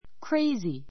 crazy A2 kréizi ク レ イズィ 形容詞 比較級 crazier kréiziə r ク レ イズィア 最上級 craziest kréiziist ク レ イズィエ スト ❶ 気の狂 くる った, 頭の変な （mad） ; ばかげた ❷ 熱狂 ねっきょう した, 夢中の He is crazy about jazz music.